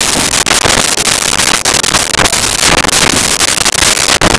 Door Antique Open Close 02
Door Antique Open Close 02.wav